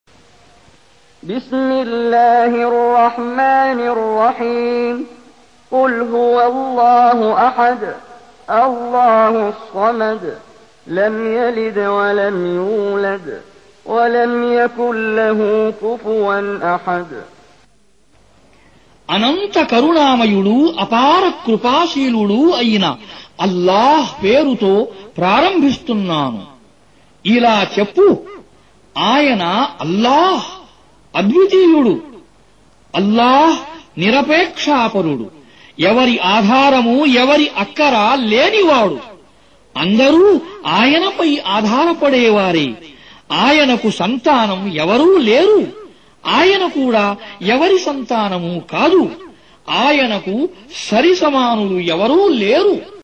Audio Quran Tarjuman Translation Recitation
112. Surah Al-Ikhl�s or At-Tauh�d سورة الإخلاص N.B *Surah Includes Al-Basmalah Reciters Sequents تتابع التلاوات Reciters Repeats تكرار التلاوات